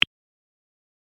click-short.ogg